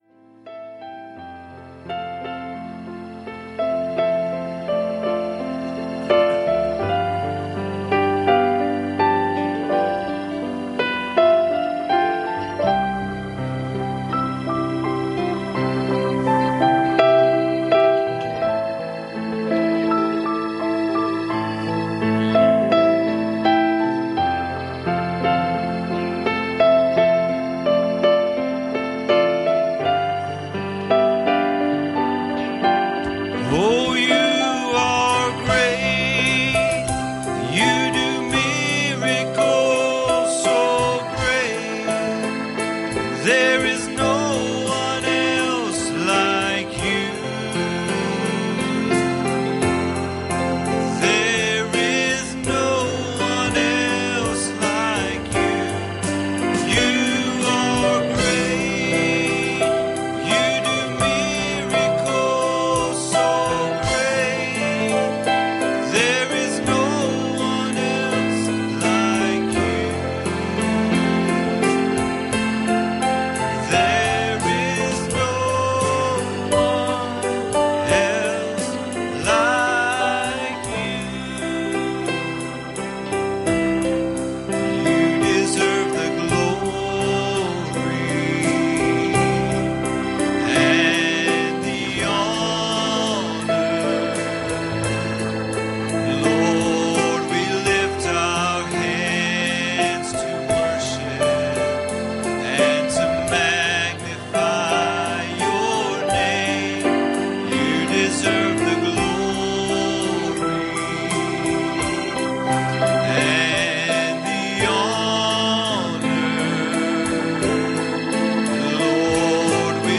Passage: 1 Samuel 18:6 Service Type: Sunday Morning